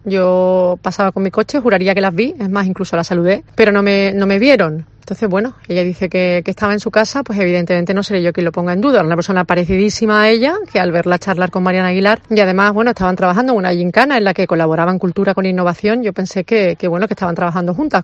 Escucha la reactificación de la portavoz de Vox , Paula Badanelli